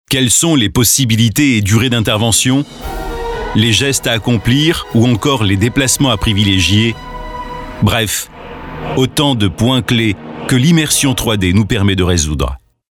Voix Off Médium
Sprechprobe: Industrie (Muttersprache):